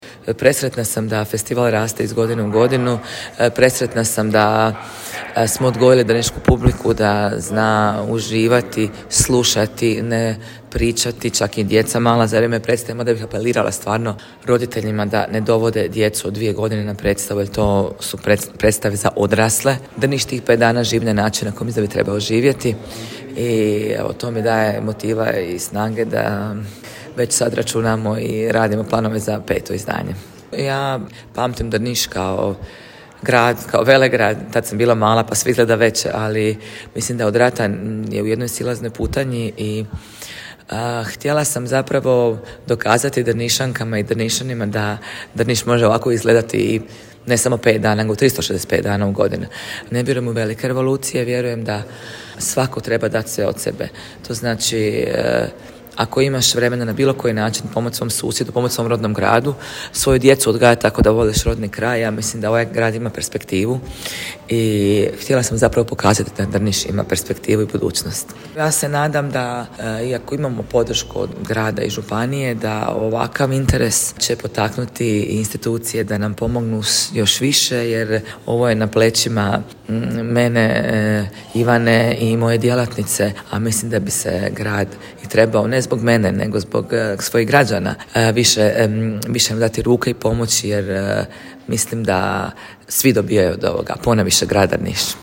Ecija Ojdanić nam je po završetku festivala kazala:
Ecija-Ojdanic-2407-izjava-vijesti.mp3